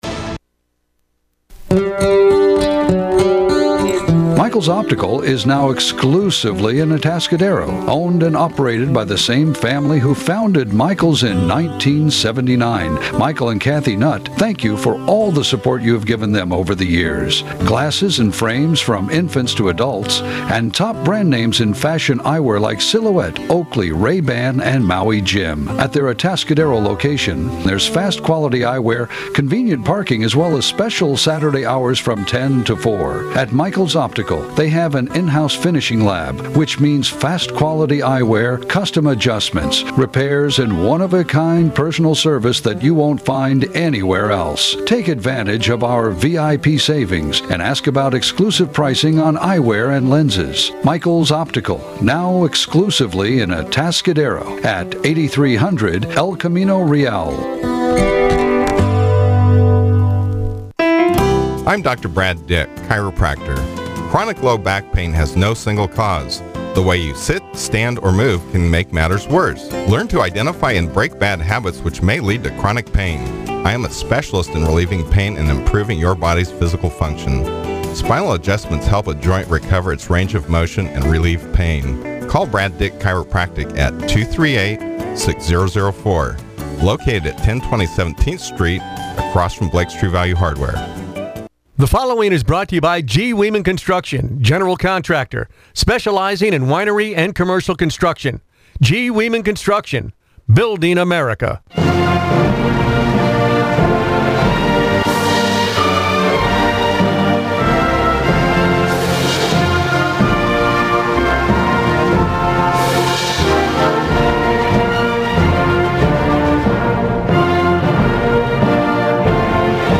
The Morning Exchange; North County’s local news show airs 6 a.m. to 9 a.m. every weekday.